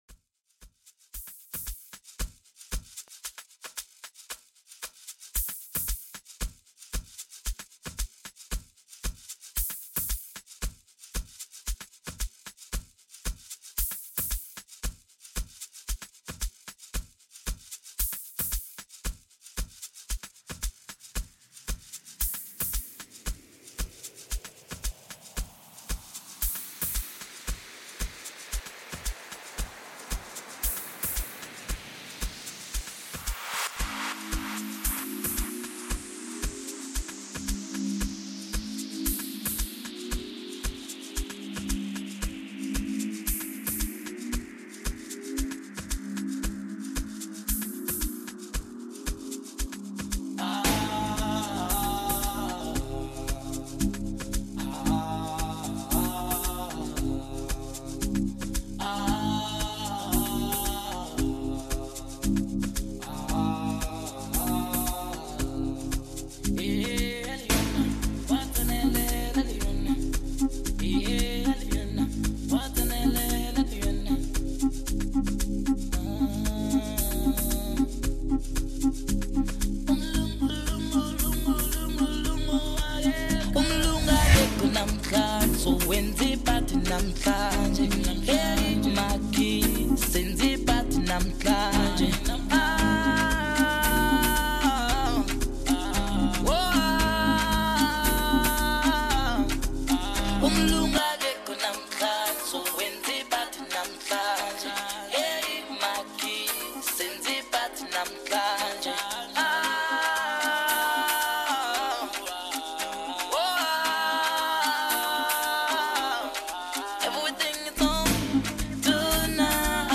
mixtape
features many feel good piano songs